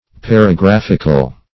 Search Result for " paragraphical" : The Collaborative International Dictionary of English v.0.48: Paragraphic \Par`a*graph"ic\, Paragraphical \Par`a*graph"ic*al\, a. Pertaining to, or consisting of, a paragraph or paragraphs.